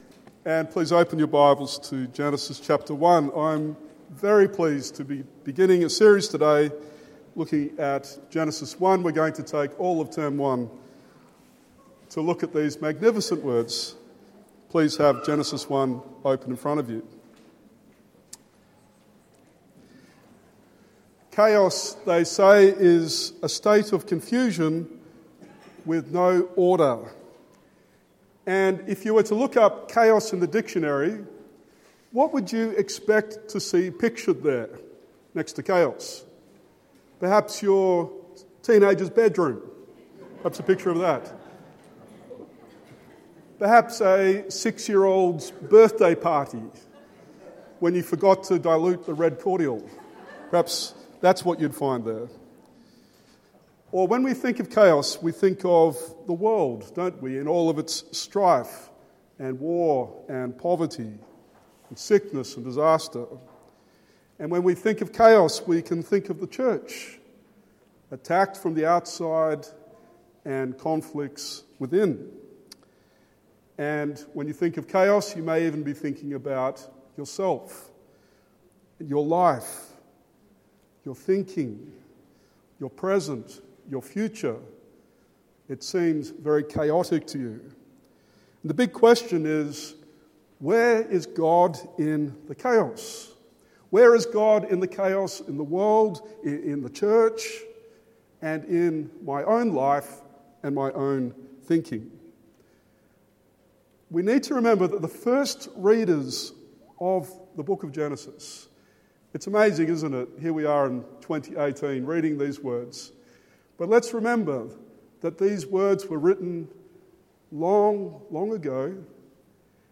Genesis 1:1-2:3 Sermon